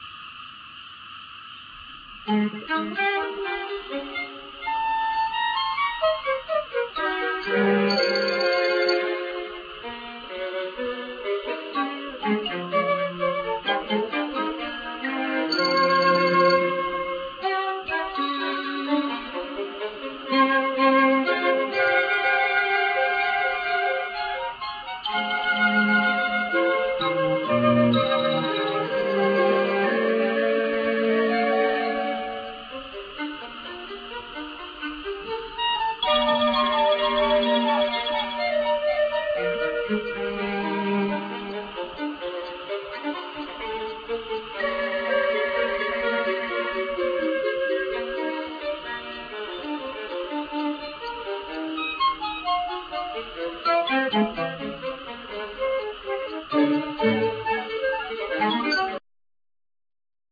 Violin
Viola
Cello
Double bass
Vocals
Flute
Clarinet
Guitars
Piano
Marimba,Vibraphone
Percussions